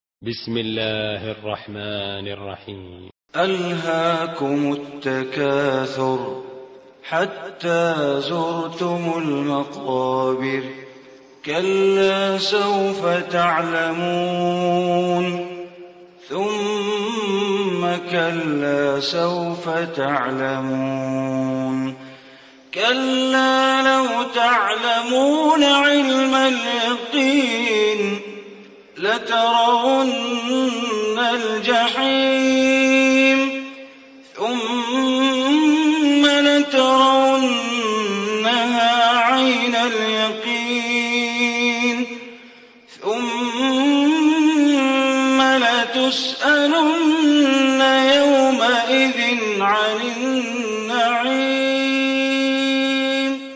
Surah Takasur Recitation by Sheikh Bandar Baleela
Surah Takasur, listen online mp3 tilawat / recitation in Arabic recited by Imam e Kaaba Sheikh Bandar Baleela.